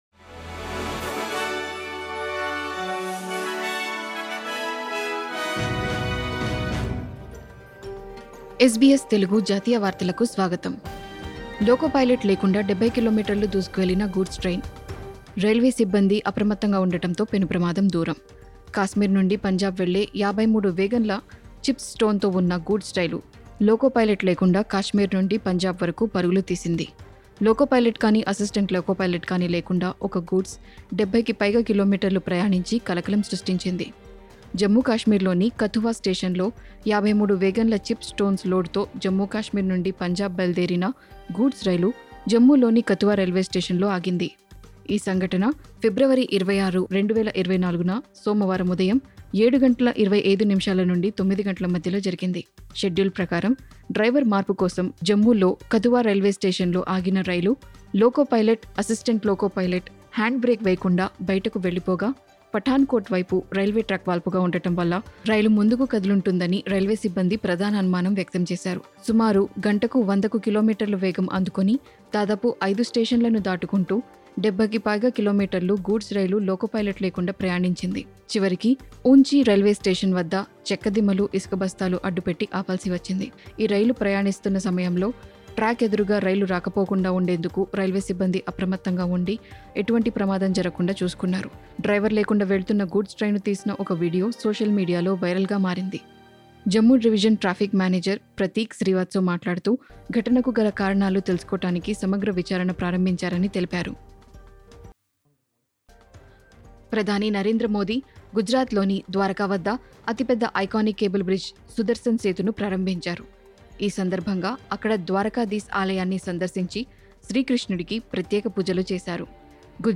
ఈ వారం భారత్ జాతీయ వార్తలు.